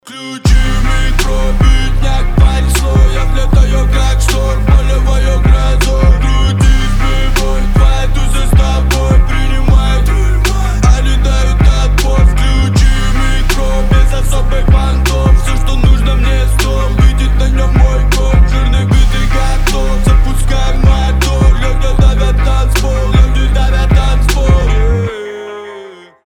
• Качество: 320, Stereo
Хип-хоп
пацанские